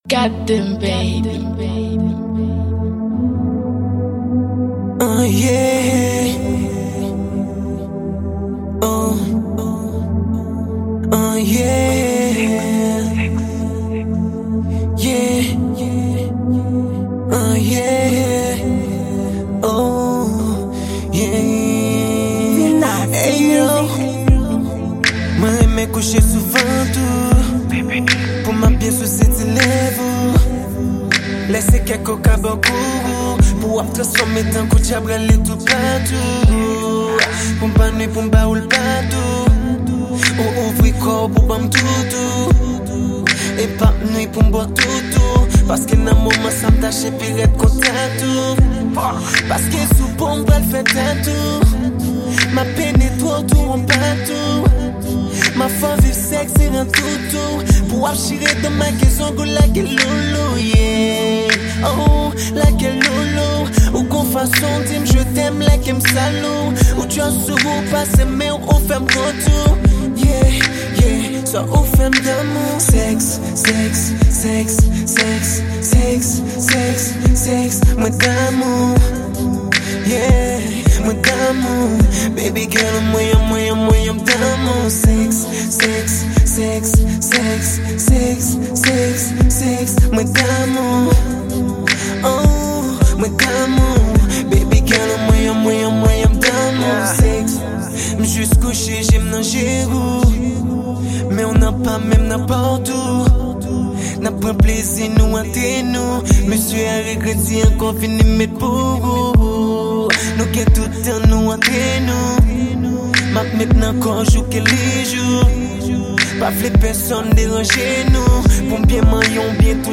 Genre: R&B.